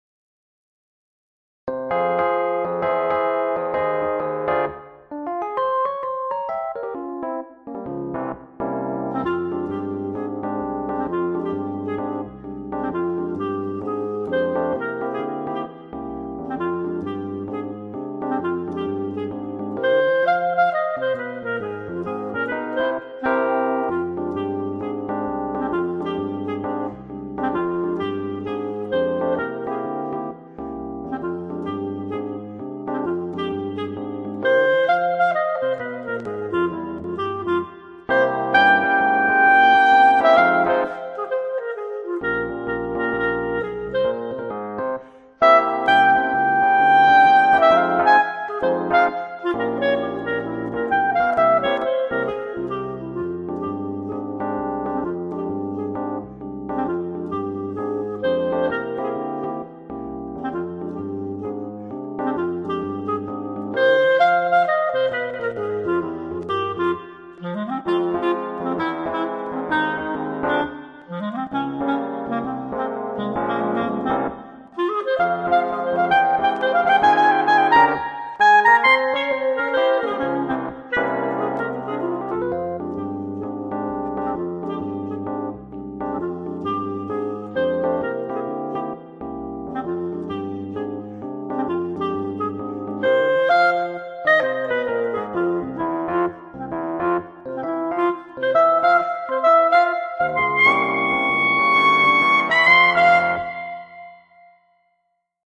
Piano Accompaniment for Bb and Eb instruments £5.00 pdf